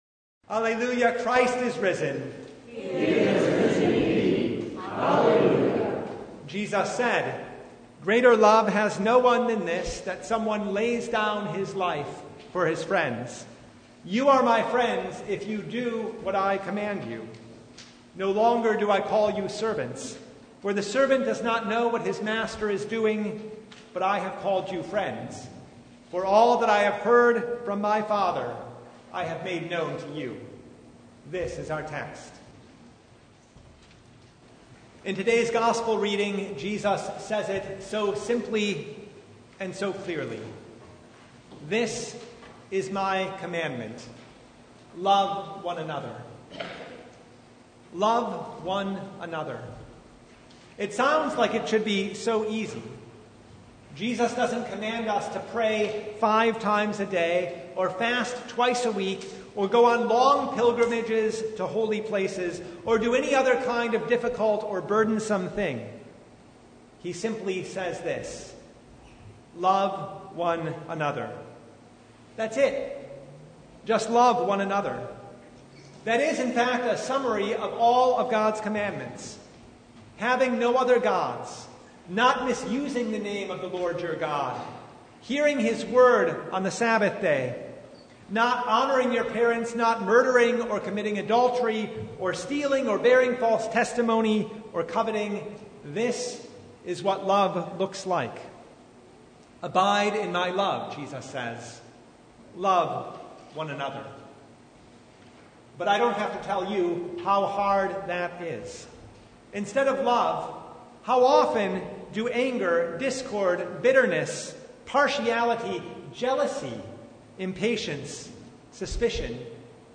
John 15:9-17 Service Type: Sunday “Love one another” sounds like it should be simple